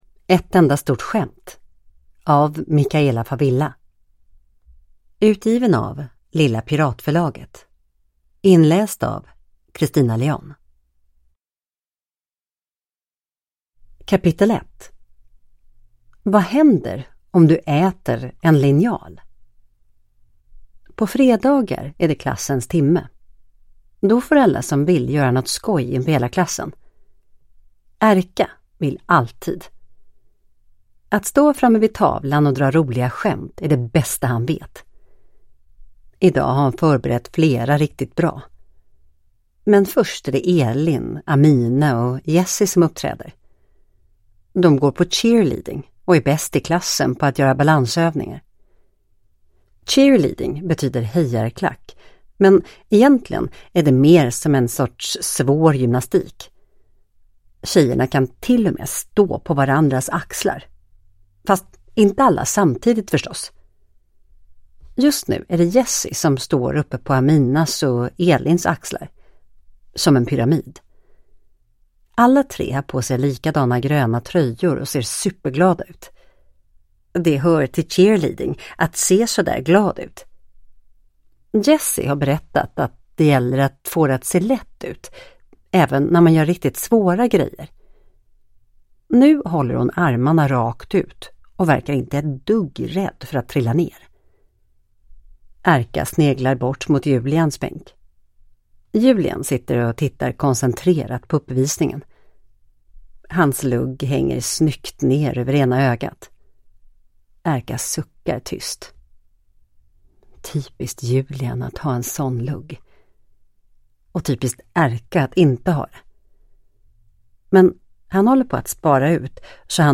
Ett enda stort skämt (ljudbok) av Micaela Favilla